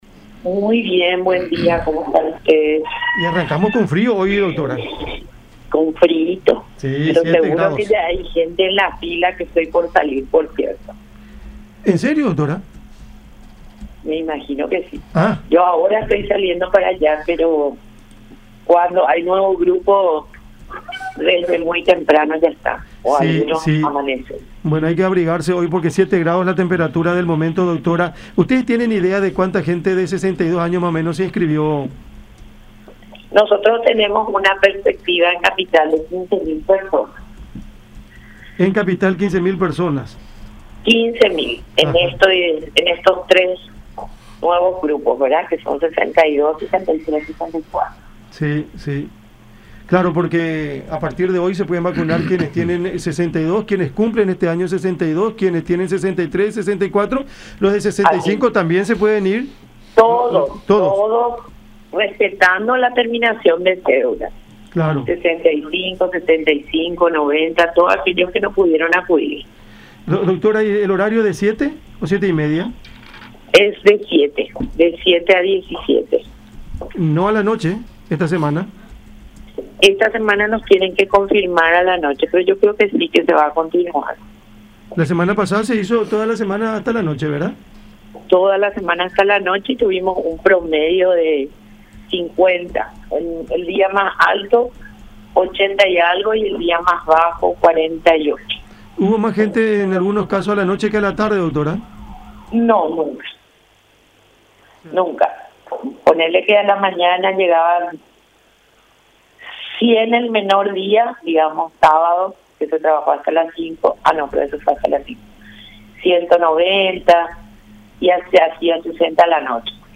en diálogo con Cada Mañana por La Unión.